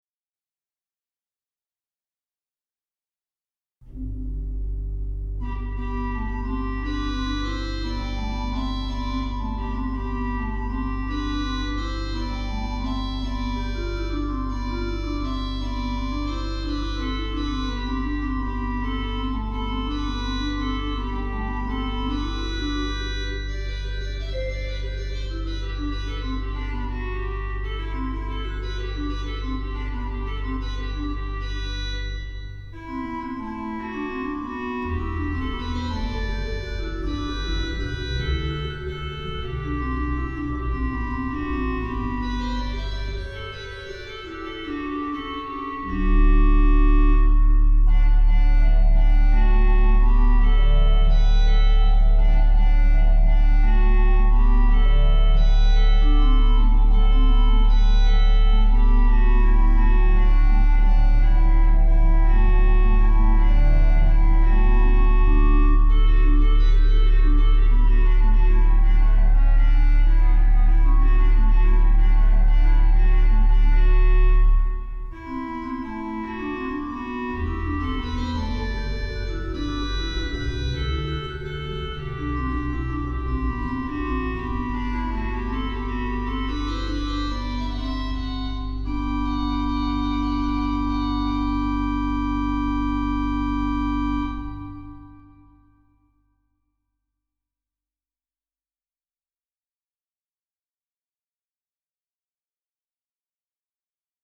in_dulci_jubilo_organ.mp3